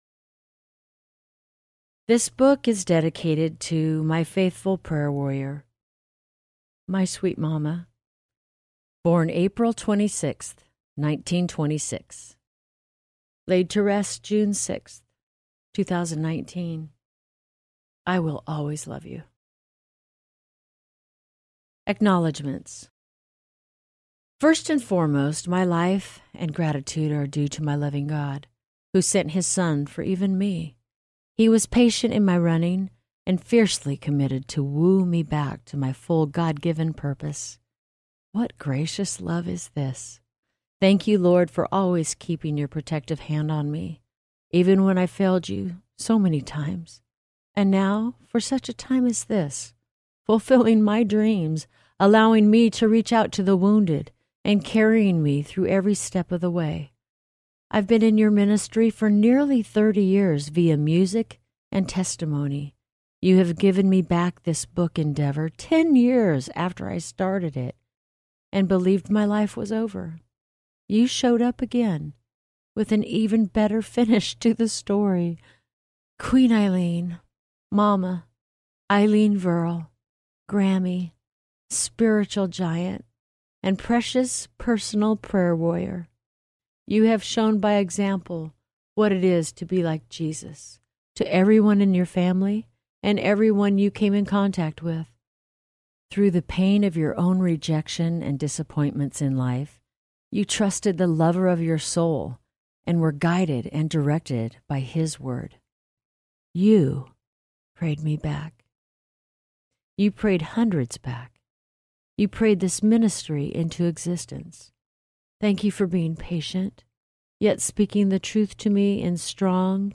No Turning Back, Regardless Audiobook
Narrator